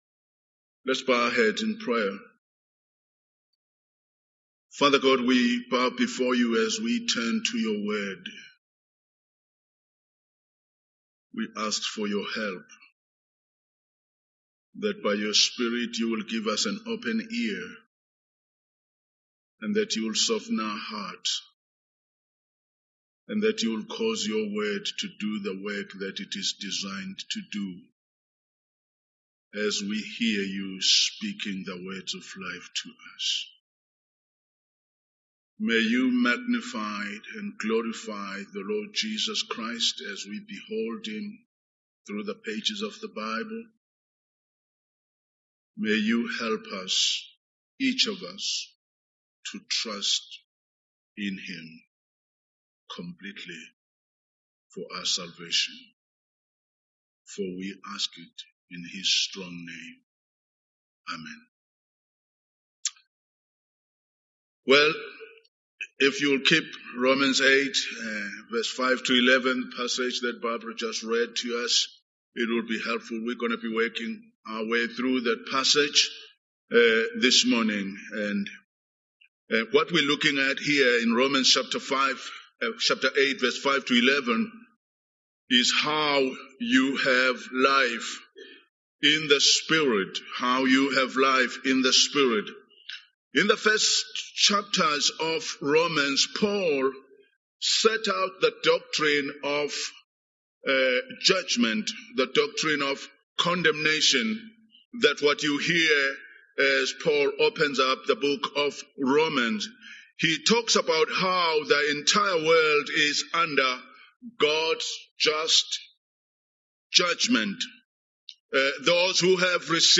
English Sermon Topics